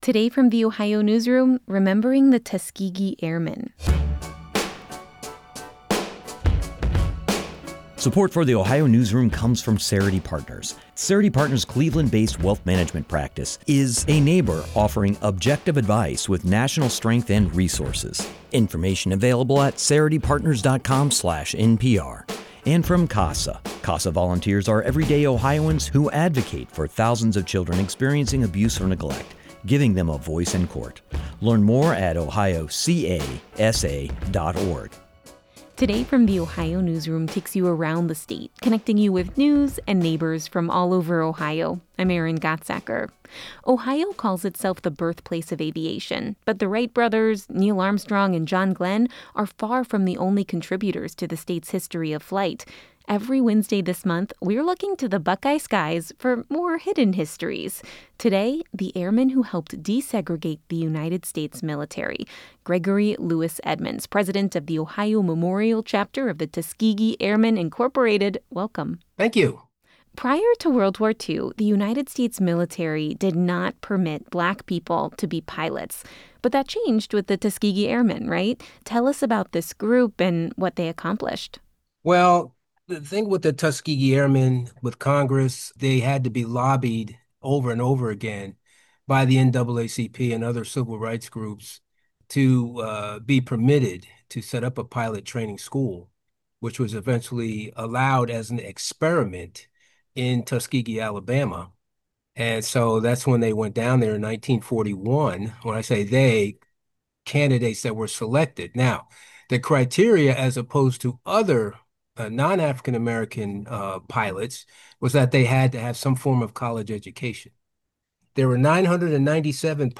This interview has been lightly edited for clarity and brevity.